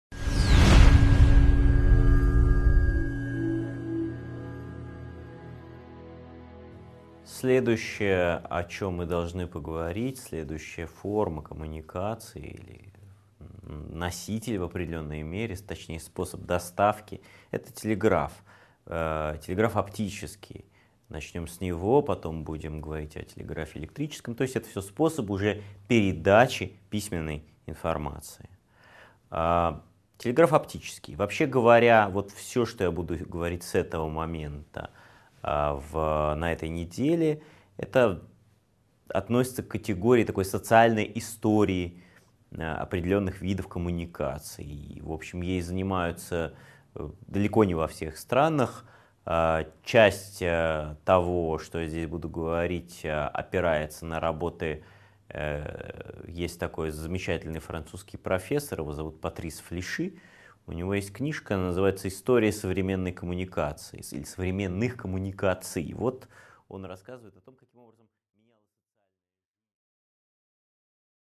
Аудиокнига 4.6 Телеграф оптический | Библиотека аудиокниг